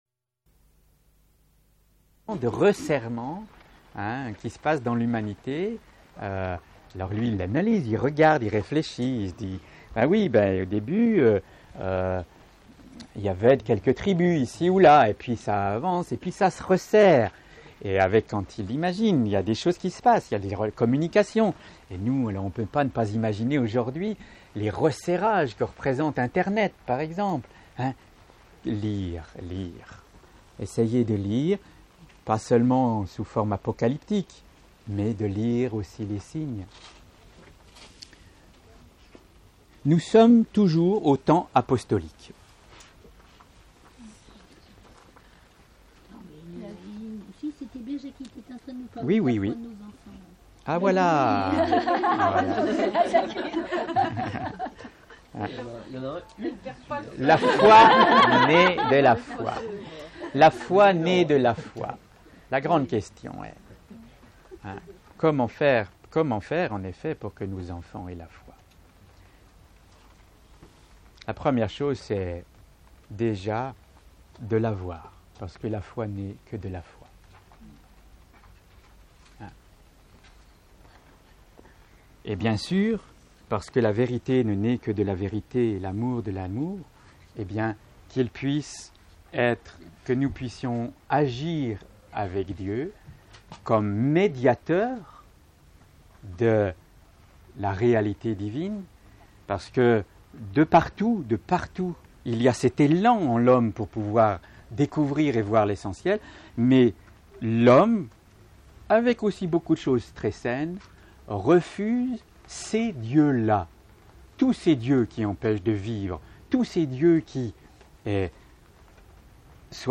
Camping l'Hirondelle - Diois avec Teilhard et Laberthonnière jour 1 Télécharger la conférence jour2 Télécharger la conférence jour 3